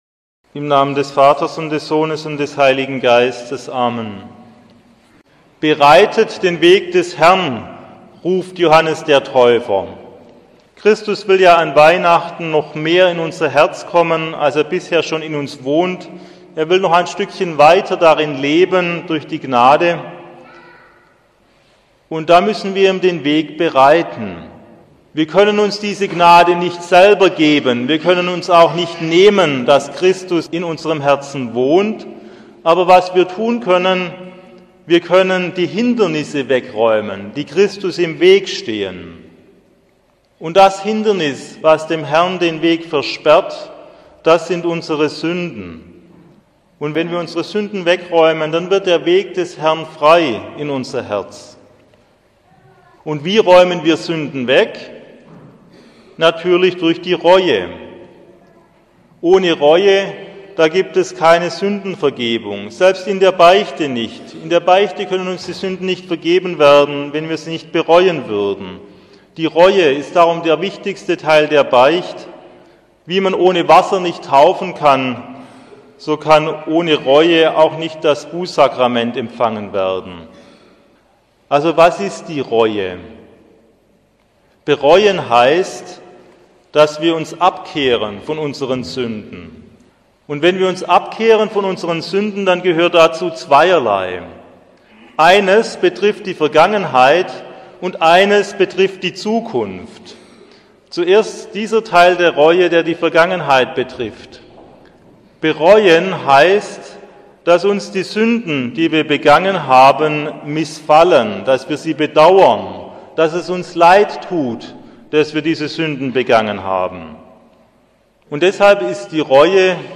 Die richtige Reue für eine gültige Beichte ~ Katholische Predigten & Vorträge Podcast